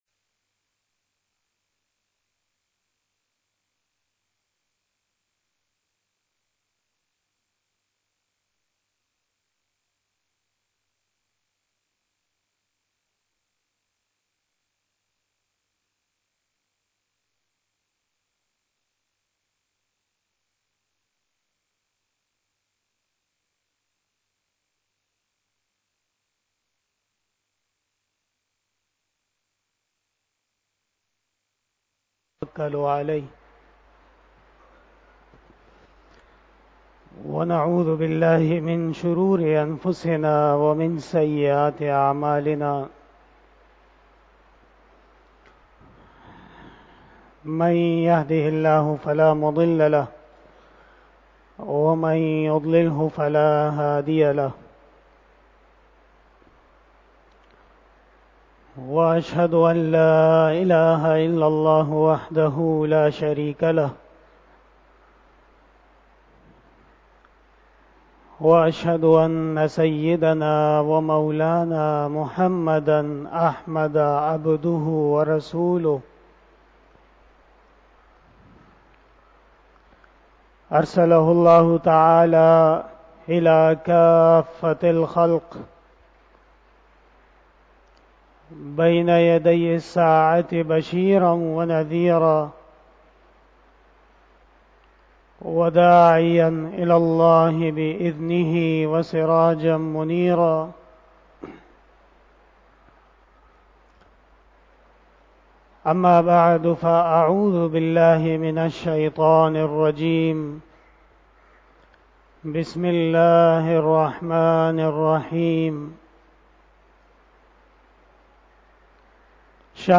13 BAYAN E JUMA TUL MUBARAK 01 April 2022 (28 shaban 1443H)
02:14 PM 406 Khitab-e-Jummah 2022 --